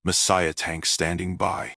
This product is the supporting voice of my self-made unit "Messiah Tank", which is completely self dubbed and not taken from any RTS in the same series.
These voices were completely recorded by me personally, with almost no post-processing, which makes them very versatile.
My spoken English may have a taste of Chinglish.